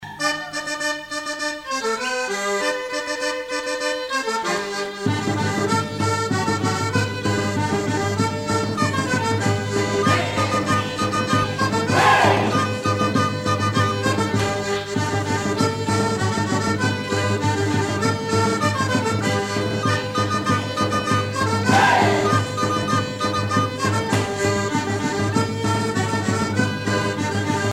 danse : sicilienne
Pièce musicale éditée